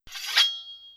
Melee Weapon Draw 3.wav